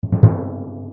level_end_sfx.mp3